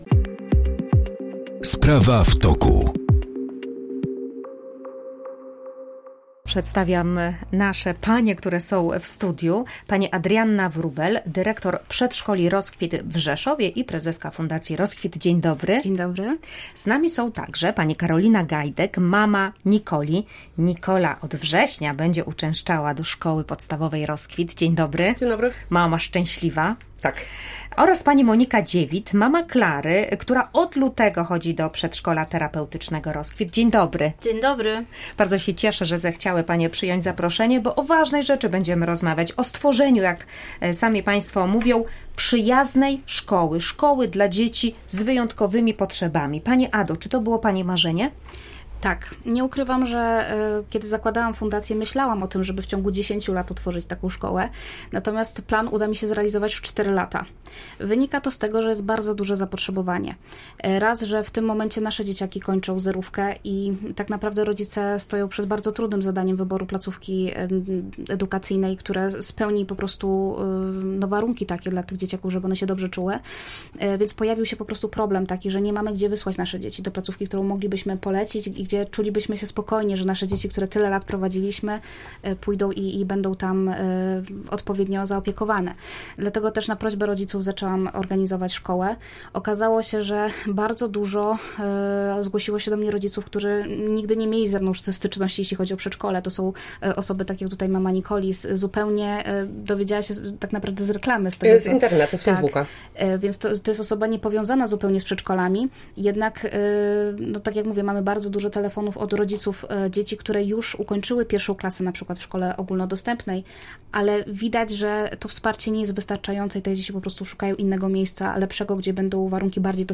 O czym opowiedzą przedstawiciele Fundacji i rodzice.